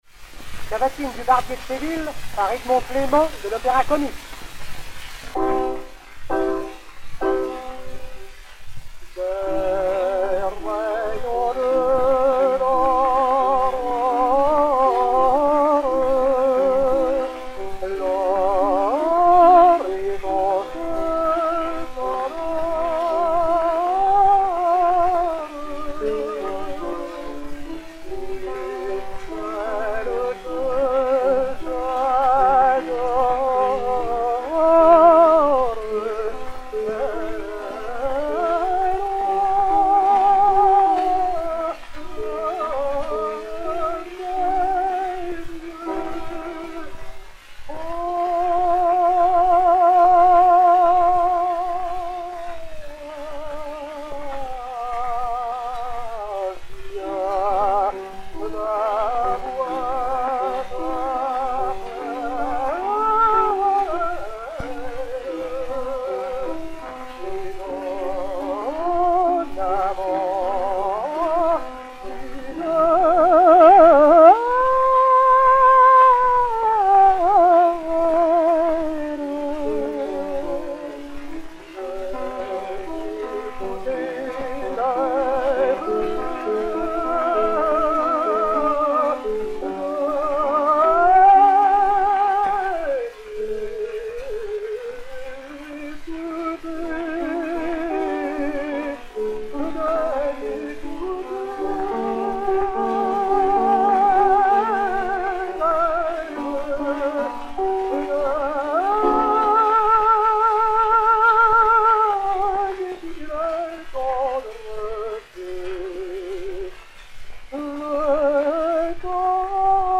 Edmond Clément (Almaviva) [avec dédicace] et Orchestre
XP 2235, enr. à Paris vers 1905